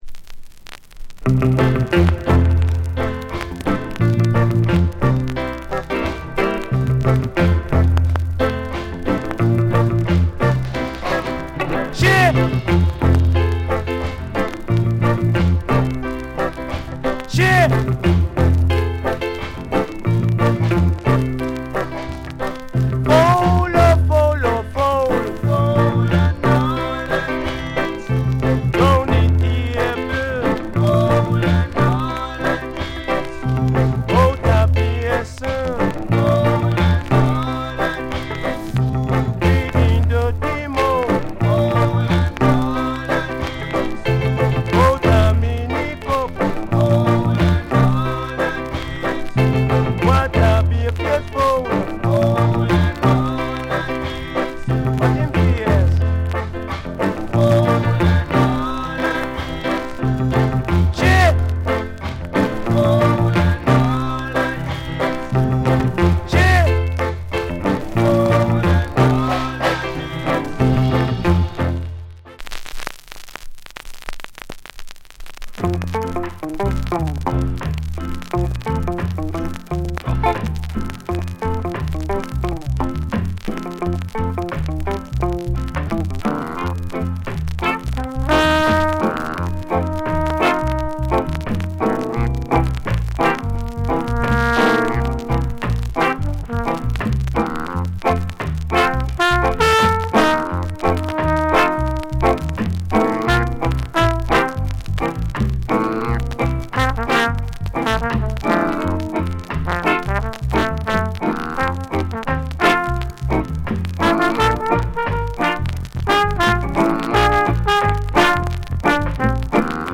Genre Rock Steady / [A] Male Vocal [B] Inst
トロンボーン・カット。